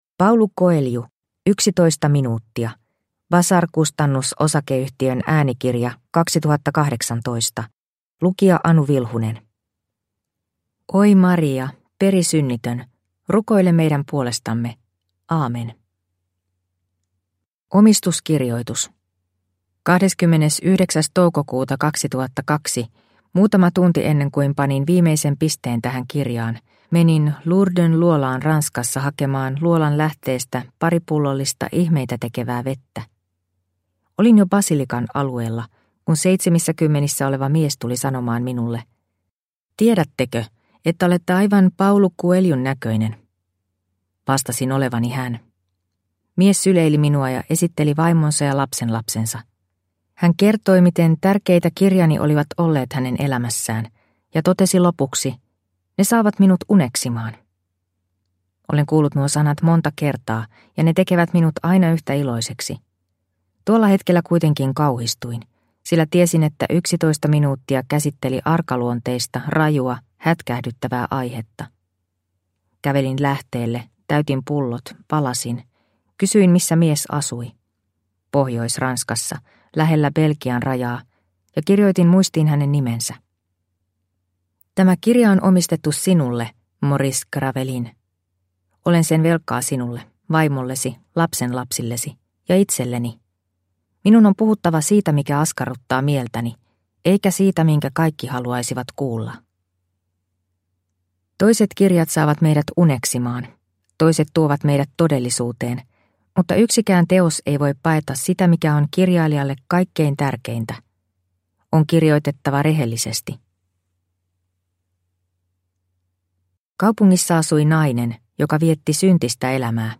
Yksitoista minuuttia – Ljudbok